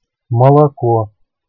Ниже приведены временные реализации слова при разных условиях
Оригинальная запись.